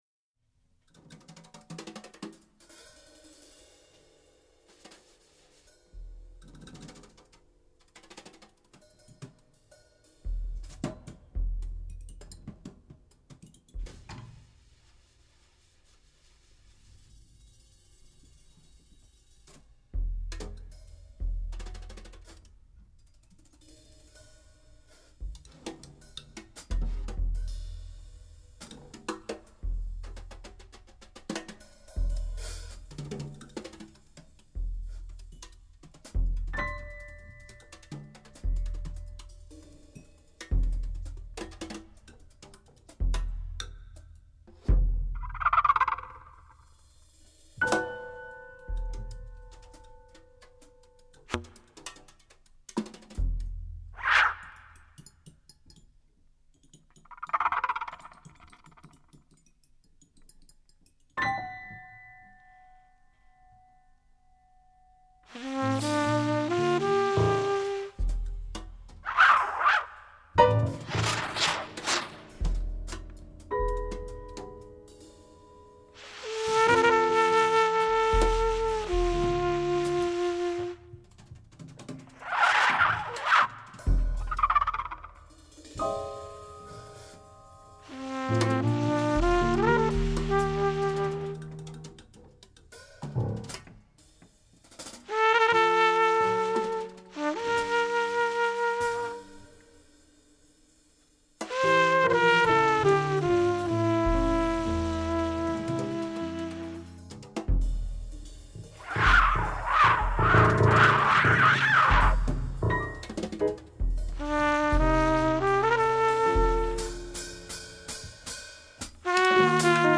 piano
trumpet